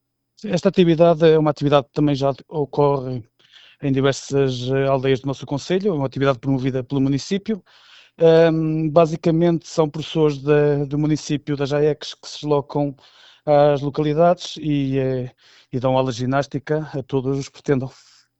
Segundo o presidente da Junta de Freguesia de Macedo de Cavaleiros, David Vaz, esta iniciativa é promovida pelo Município de Macedo de Cavaleiros: